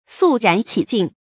sù rán qǐ jìng
肃然起敬发音
成语正音然，不能读作“yán”。